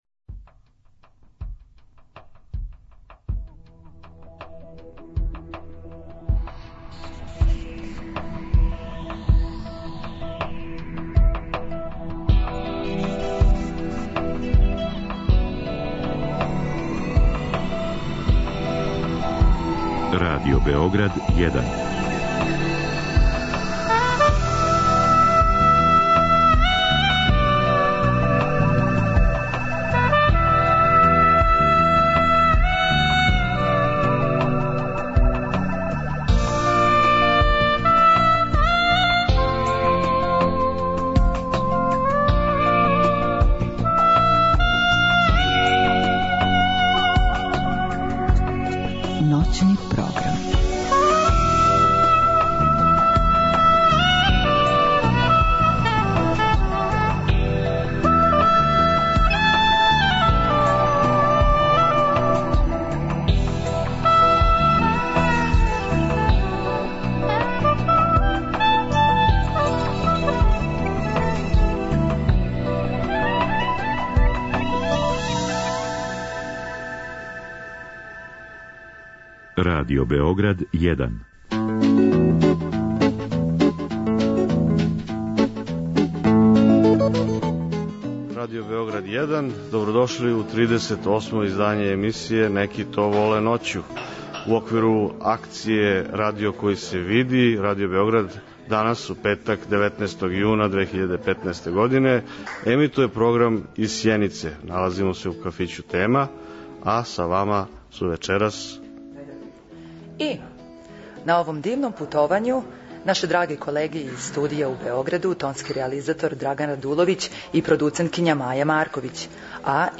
У оквиру акције „Радио који се види“, вечерас се емитујемо из Сјенице.